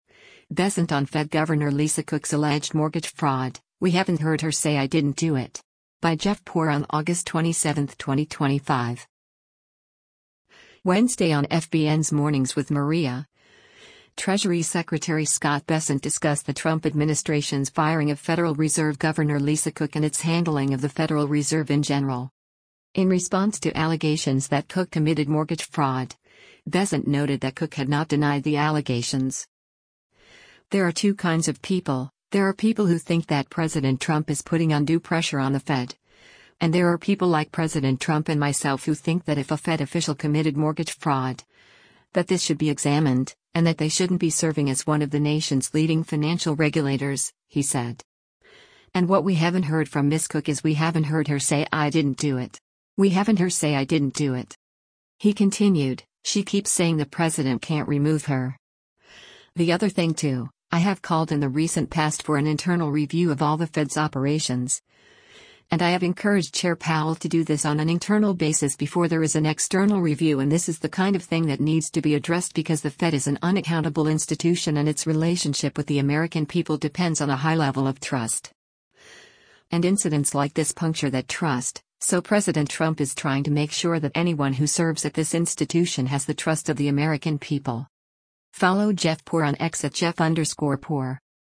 Wednesday on FBN’s “Mornings with Maria,” Treasury Secretary Scott Bessent discussed the Trump administration’s firing of Federal Reserve Governor Lisa Cook and its handling of the Federal Reserve in general.